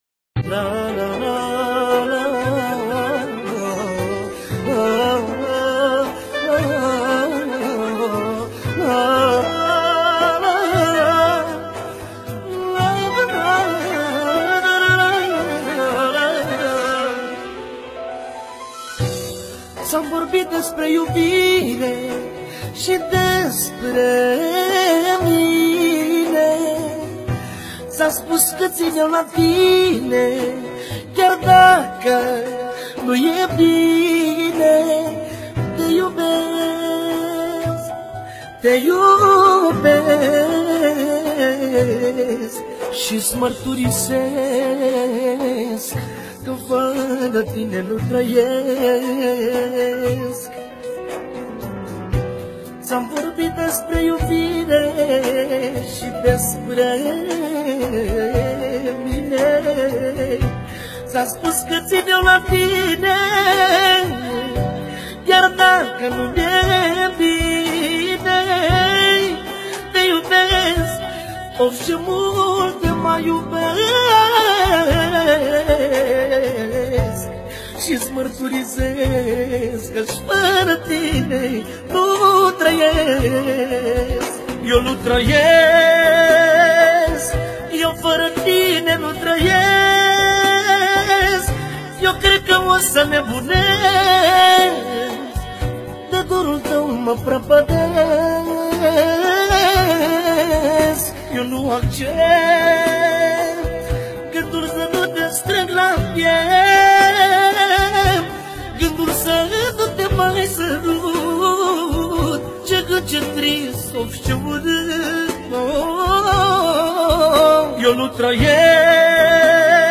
Data: 30.10.2024  Manele New-Live Hits: 0